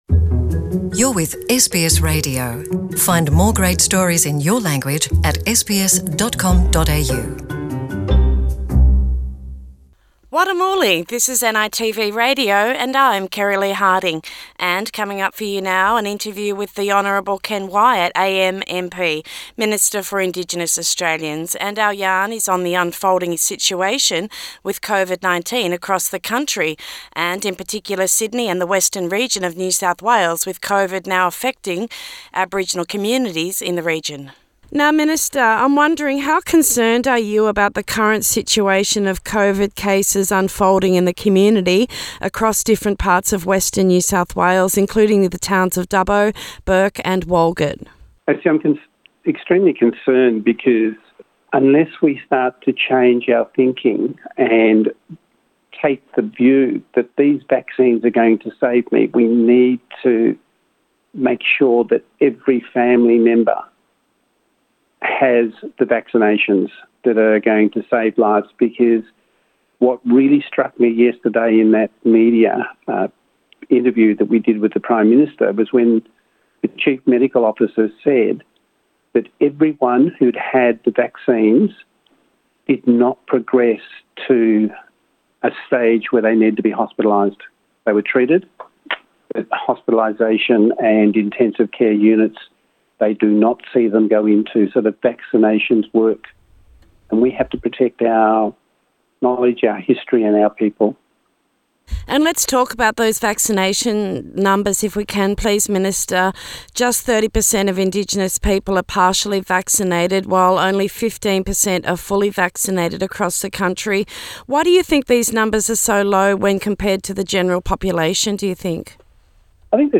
Interview with the Honourable Ken Wyatt AM MP – Minister for Indigenous Australians and this yarn is on the unfolding situation with COVID-19 across the country, in particular Sydney and the Western region of New South Wales with COVID-19 now affecting Aboriginal communities in the region.